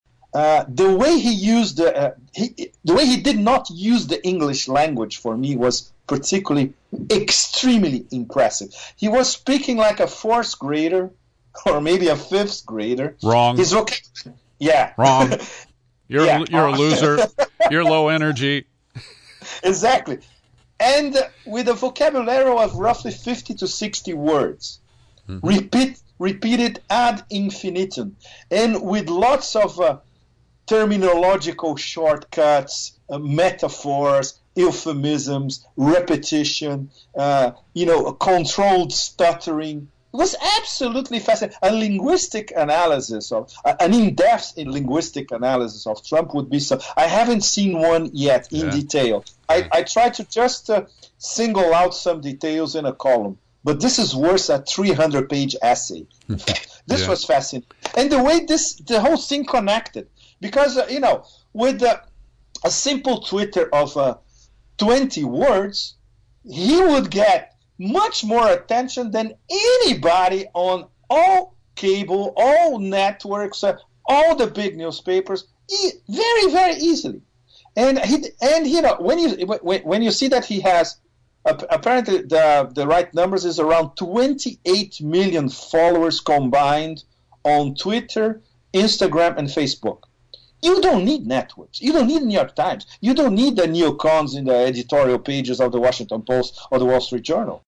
In-Depth Interview: Roving Reporter Pepe Escobar Offers an Offshore Perspective on US Election